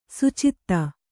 ♪ sucitta